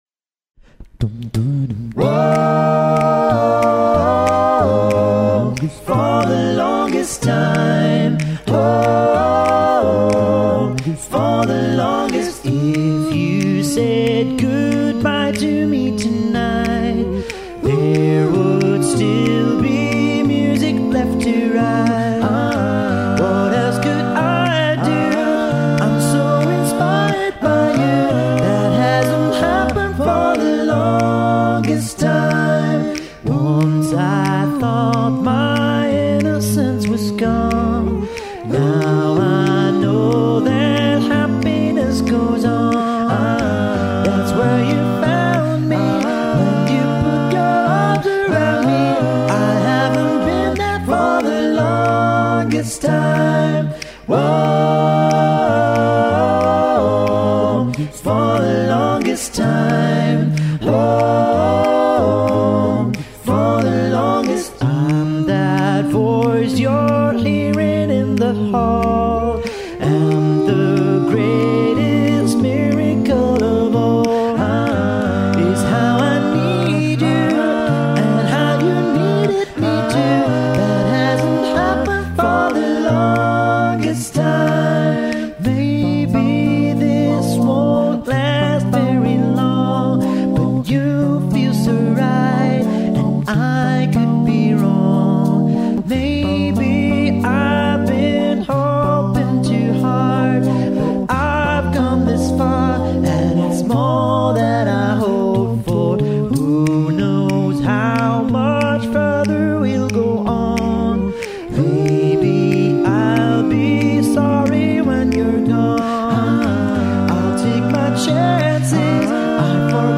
aufgenommen 2007   5-stimmig   3:14 min   2,96 mb